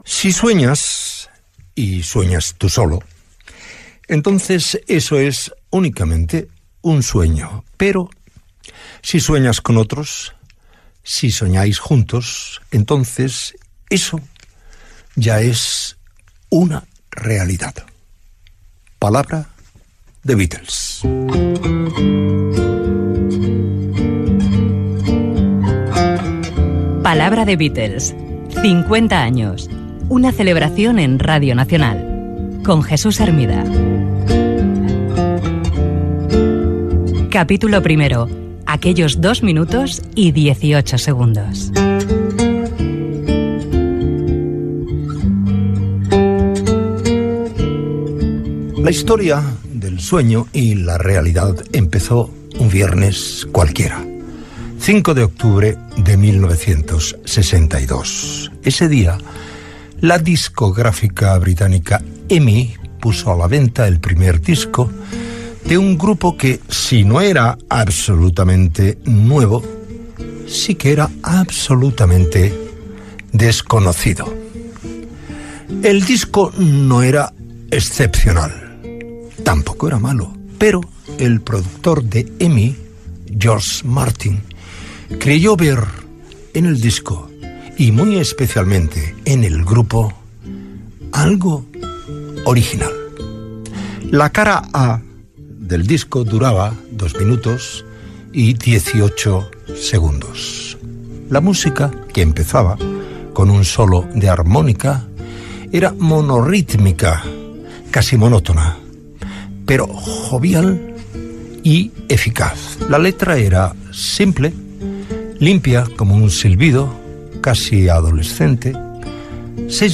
Frase The Beatles, careta de la sèrie.
Musical